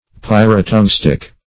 Pyrotungstic \Pyr`o*tung"stic\, a. (Chem.) Polytungstic.